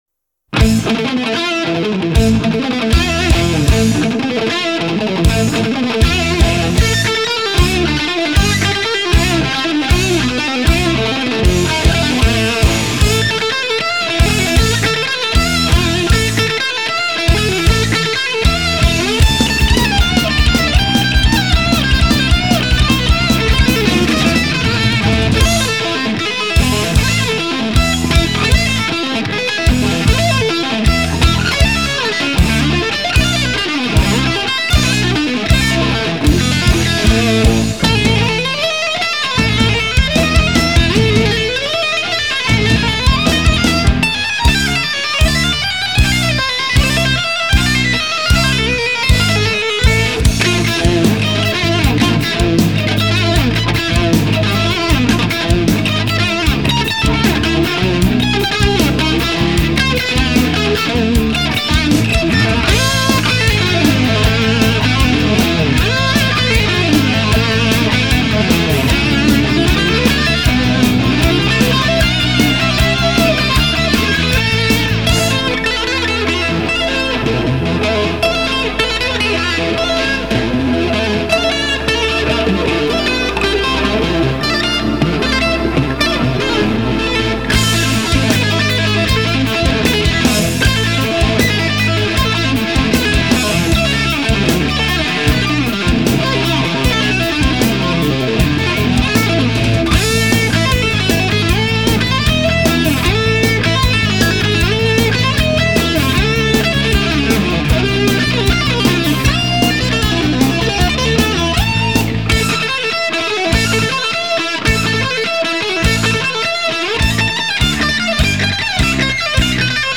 акустическая гитара
ИнтереснаЯ интерпретацияСпасибо!